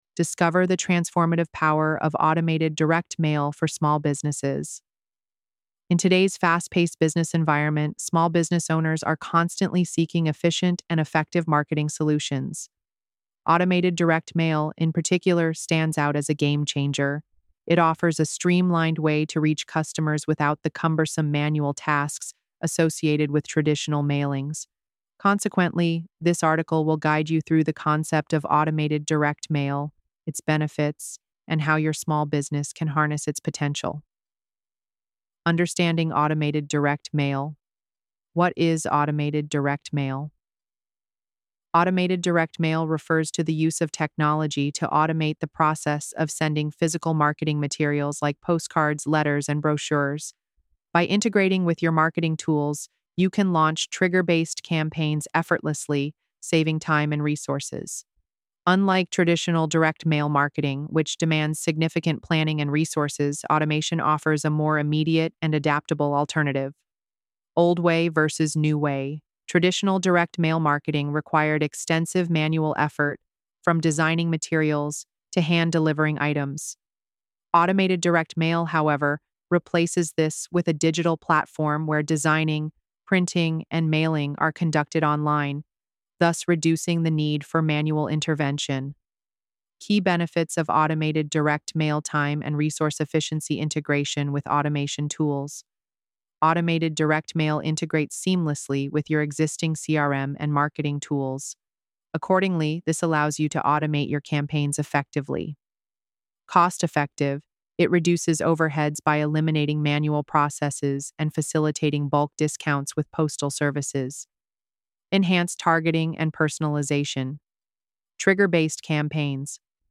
ElevenLabs_2025-05-02T02_19_28.mp3